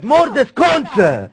Worms speechbanks
kamikaze.wav